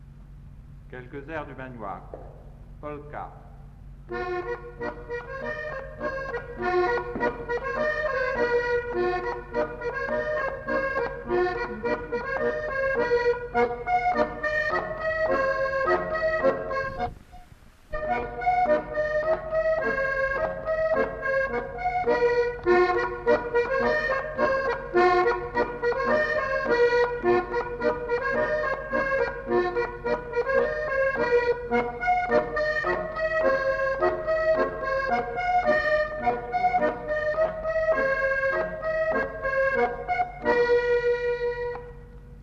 enquêtes sonores
Polka